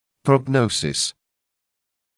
[prɔg’nəusɪs][прог’ноусис]прогноз (мн.ч. prognoses)